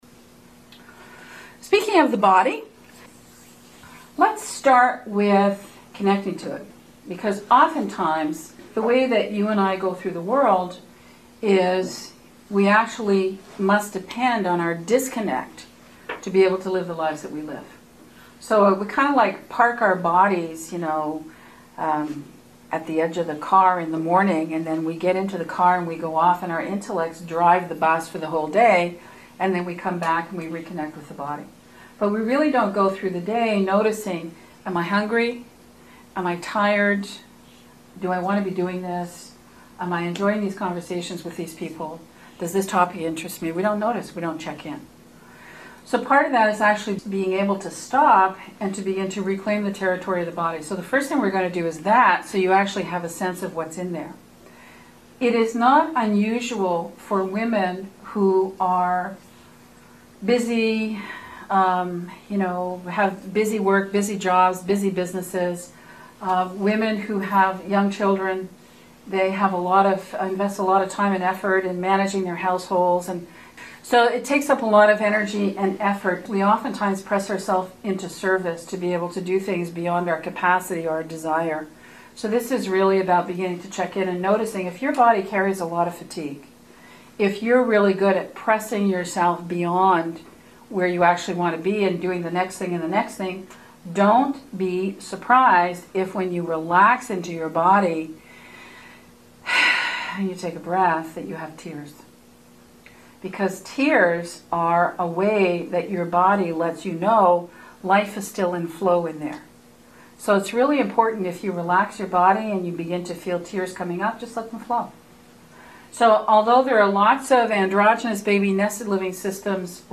This week’s conversation is an invitation for you to re-awaken and reclaim the living territory of the body. Through a process of guided reflection, the power of the pause – to make way for discovery – is revealed and experienced.